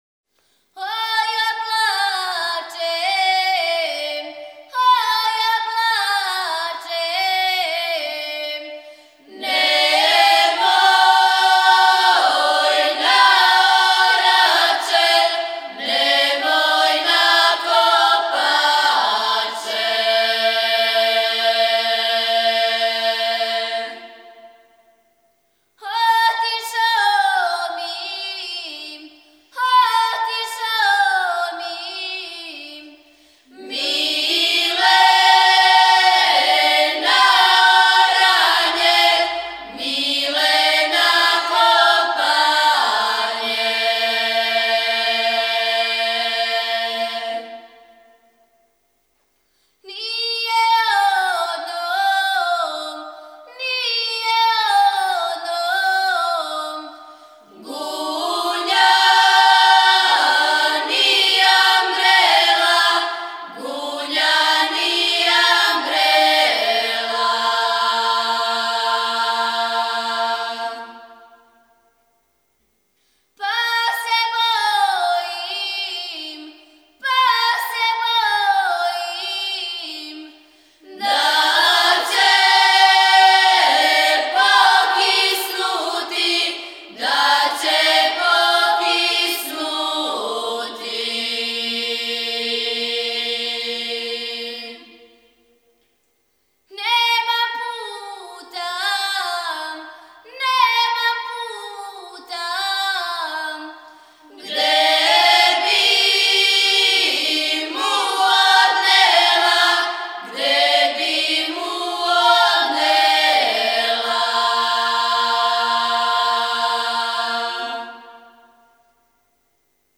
Снимци КУД "Др Младен Стојановић", Младеново - Девојачка и женска певачка група (4.5 MB, mp3) О извођачу Албум Уколико знате стихове ове песме, молимо Вас да нам их пошаљете .